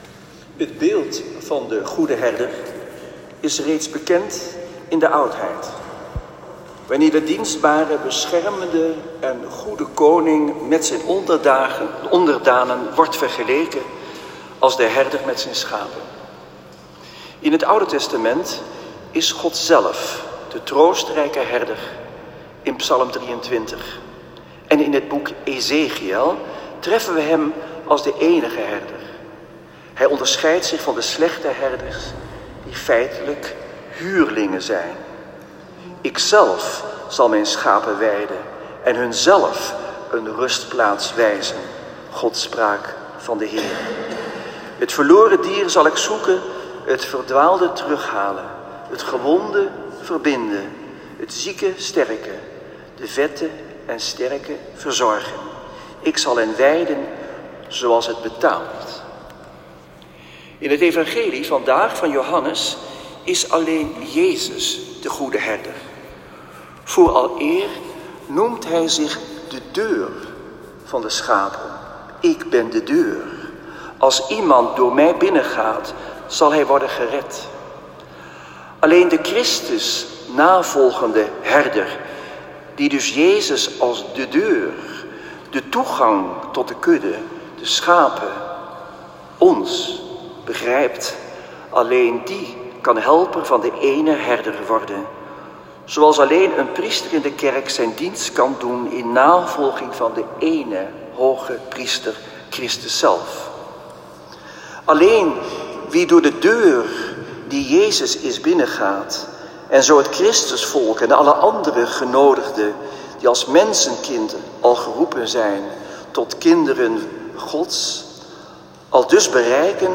Celebrant: Antoine Bodar
Preek-1.m4a